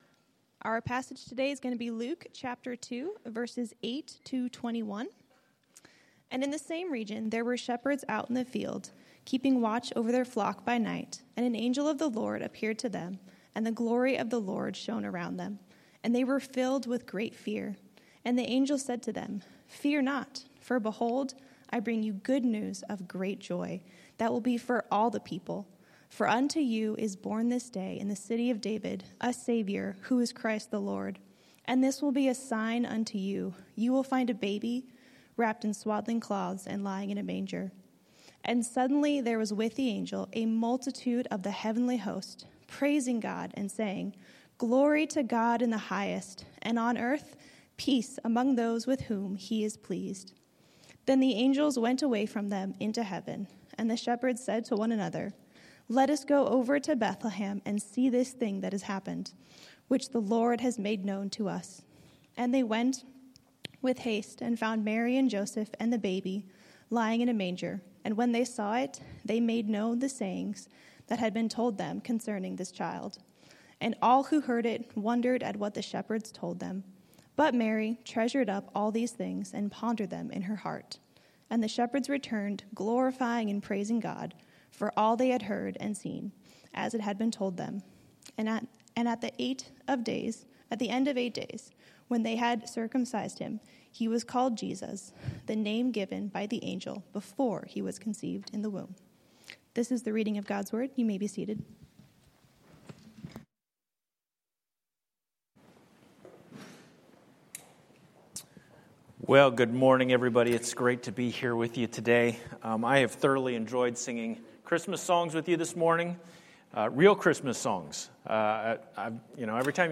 Sermons - Grace Church - Pasco